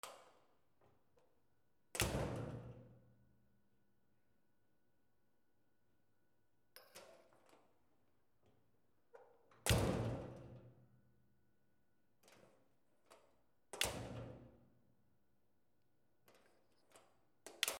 マンション 玄関ドア
/ K｜フォーリー(開閉) / K05 ｜ドア(扉)